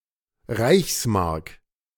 The Reichsmark (German: [ˈʁaɪçsˌmaʁk]